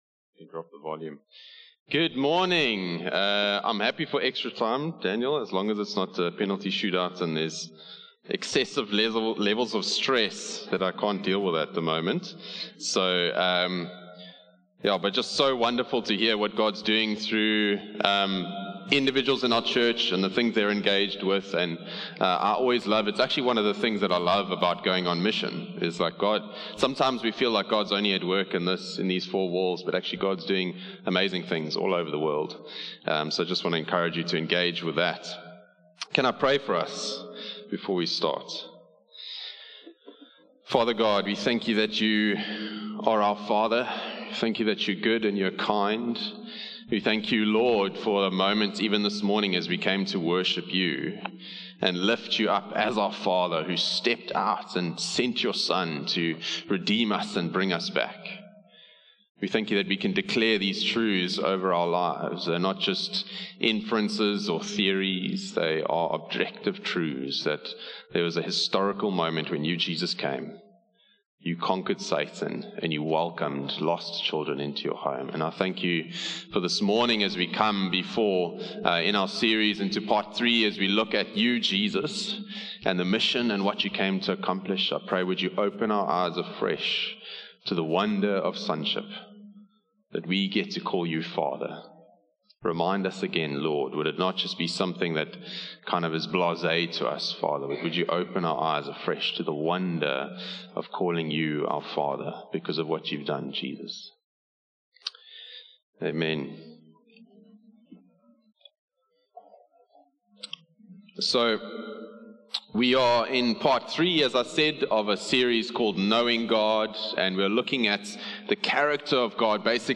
One-Hope-Sermon-2-March-2025.mp3